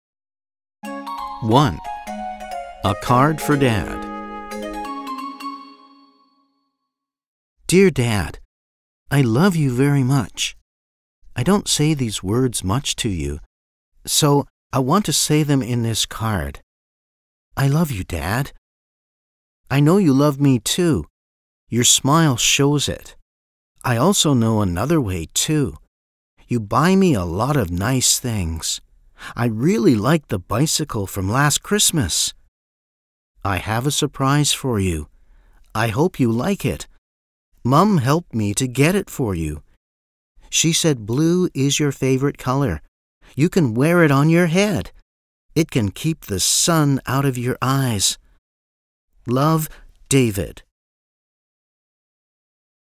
掃描書封QR Code下載「寂天雲」App，即能下載全書音檔，無論何時何地都能輕鬆聽取專業母語老師的正確道地示範發音，訓練您的聽力。